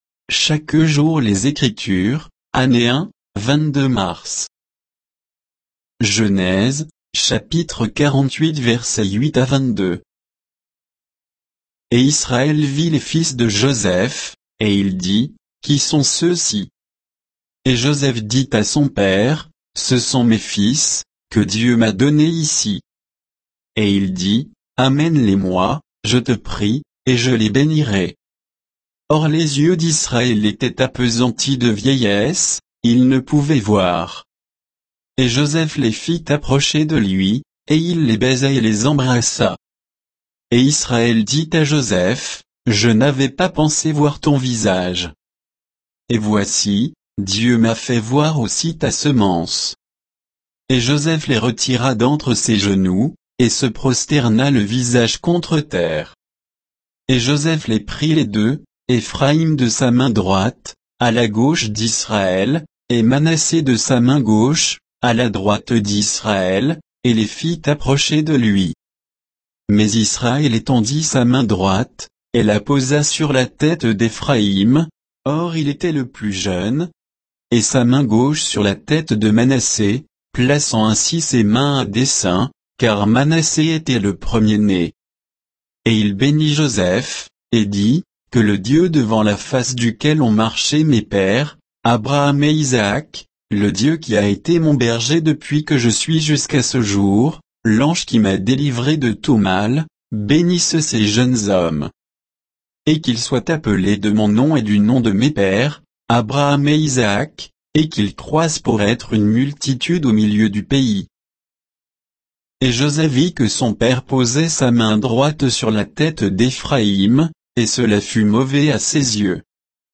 Méditation quoditienne de Chaque jour les Écritures sur Genèse 48, 8 à 22